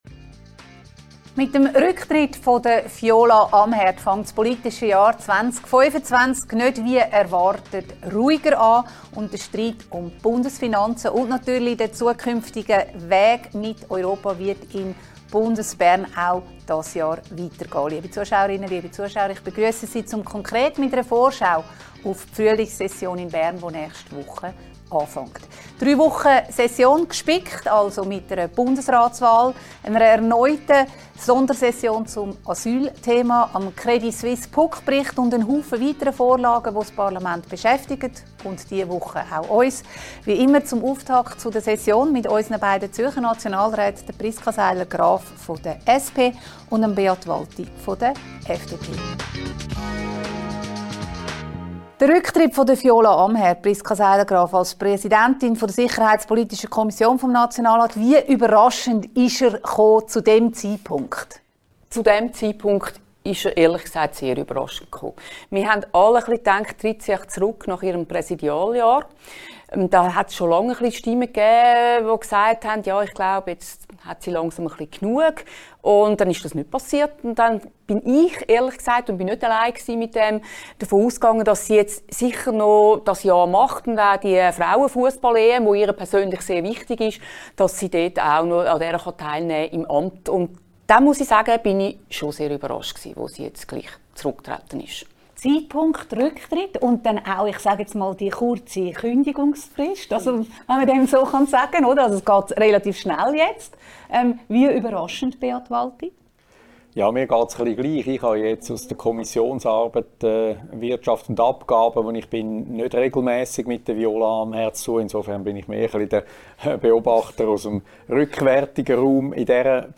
diskutiert mit den beiden Zürcher Nationalräten Priska Seiler Graf, SP und Beat Walti, FDP